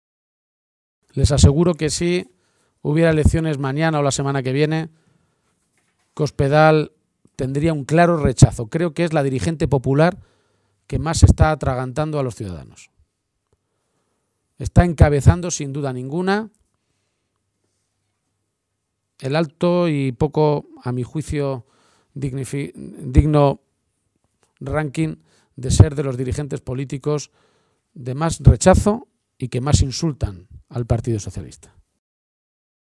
La dirección regional del Partido Socialista de Castilla-La Mancha se ha reunido esta tarde en Talavera de la Reina.